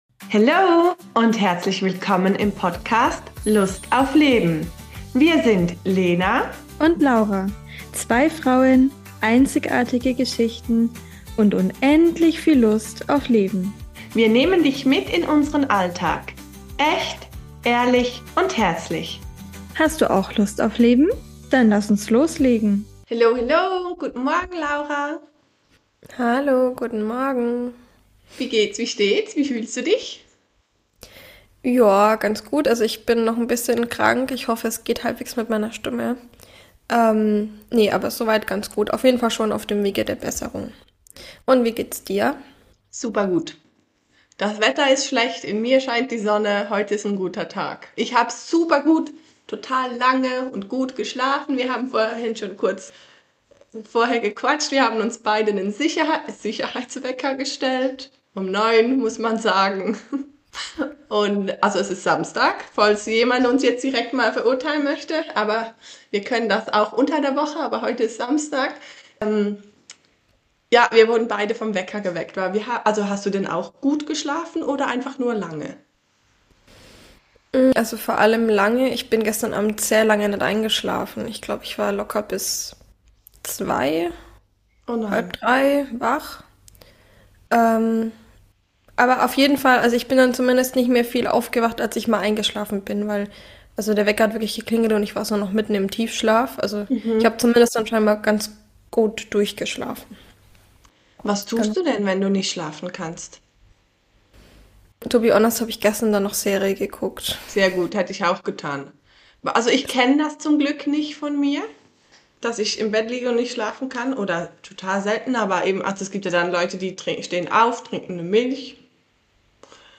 Dieses Mal wird’s tief – Astrologie trifft Human Design: pure Begeisterung, echte Erkenntnisse & jede Menge Lacher